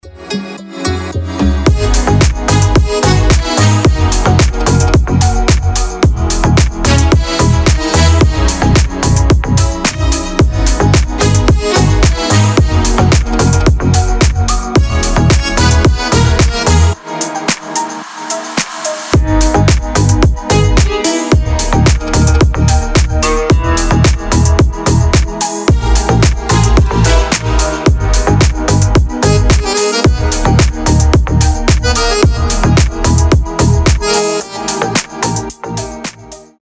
• Качество: 320, Stereo
deep house
без слов
красивая мелодия
струнные
аккордеон
инструментальные